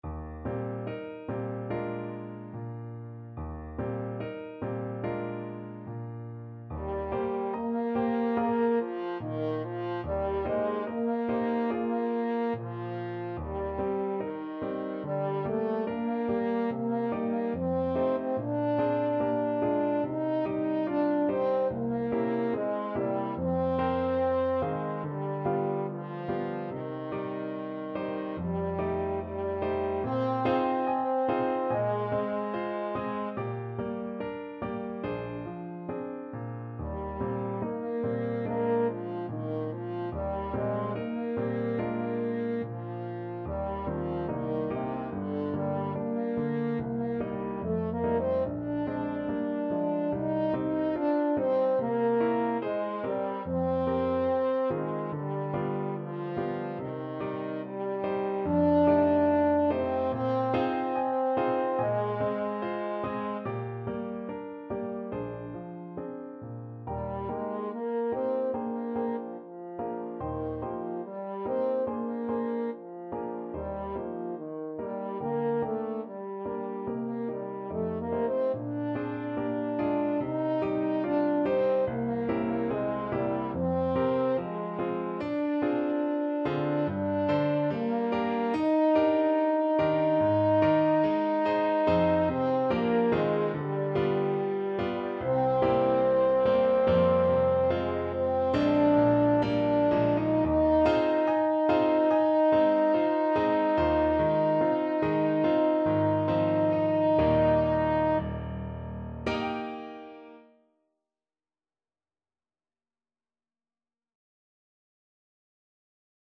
French Horn
4/4 (View more 4/4 Music)
Eb major (Sounding Pitch) Bb major (French Horn in F) (View more Eb major Music for French Horn )
~ = 72 In moderate time
Classical (View more Classical French Horn Music)